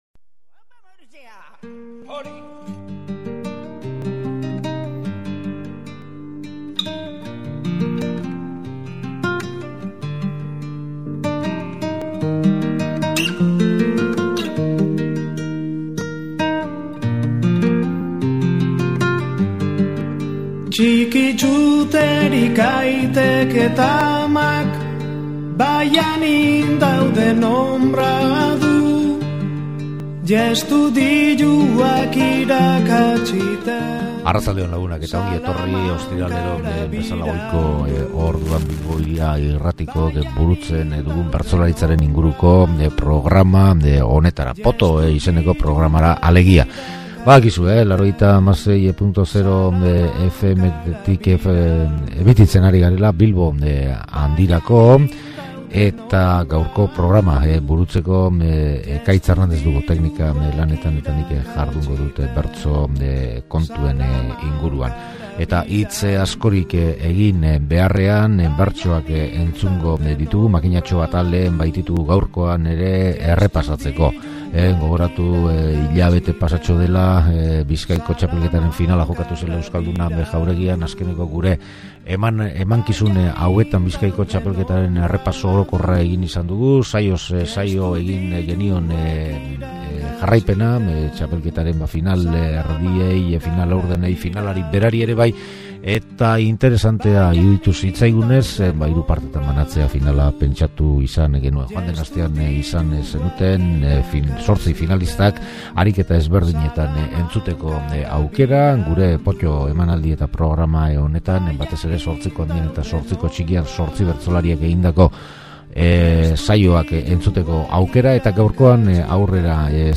Abenduko Bizkaiko finalari erreparatu diogu eta zortzi bertsolariek hamarreko txikian eta ondoren puntuei erantzuten zein emandako gaiei kantatutako aleak prestatu ditugu. Astero bezala, agendari ere erreparatu diogu.